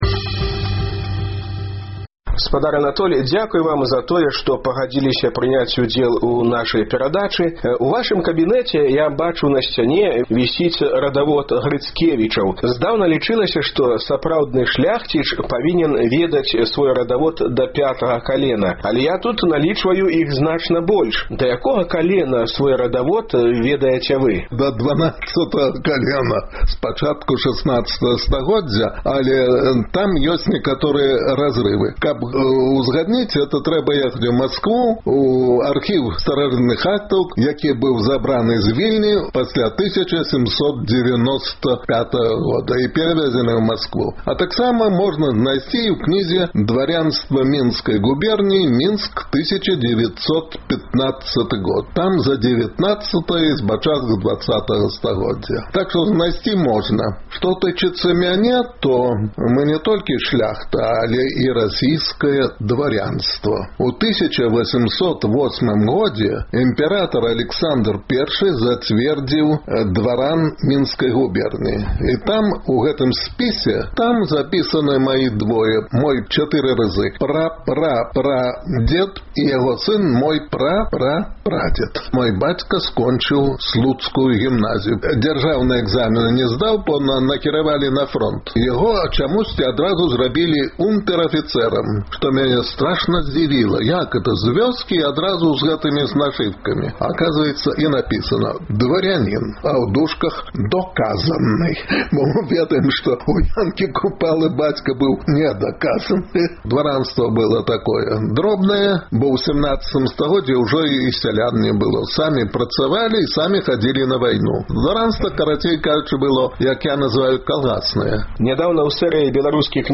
з гісторыкам гутарыць